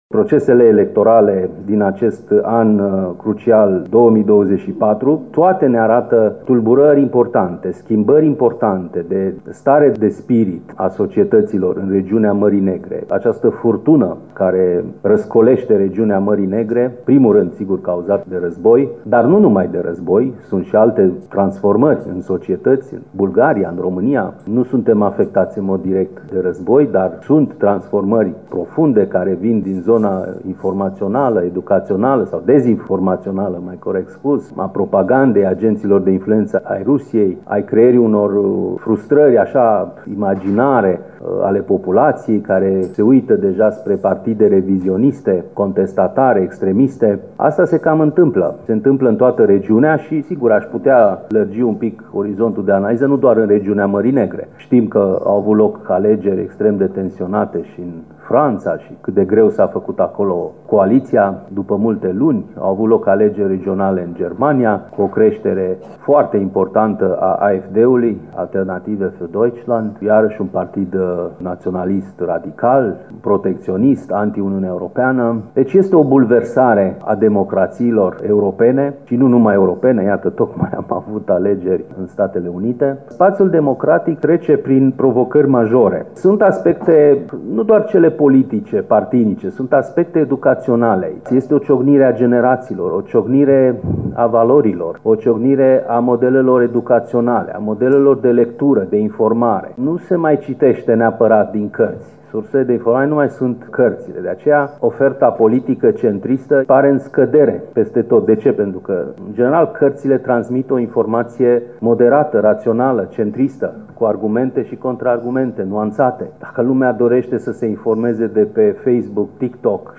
AUDIO | Rețelele sociale “modelează” felul în care o întreagă generație își culege informațiile și învață. Interviu